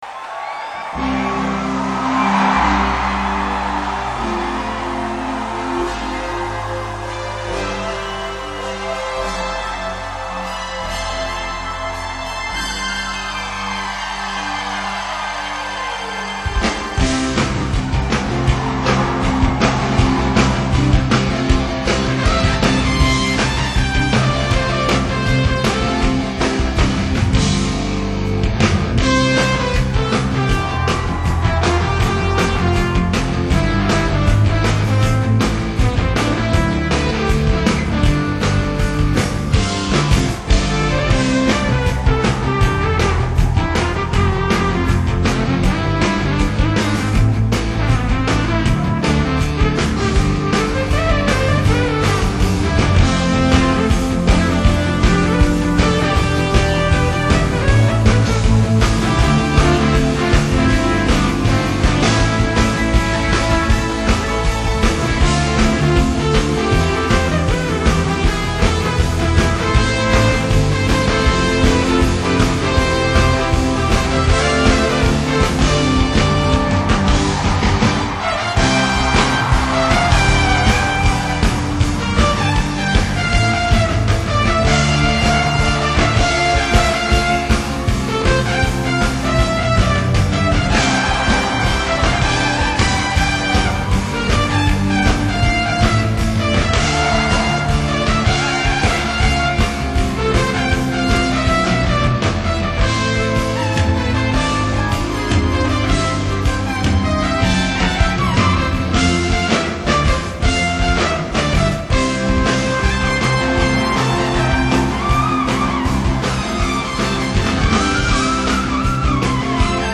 2006 내한공연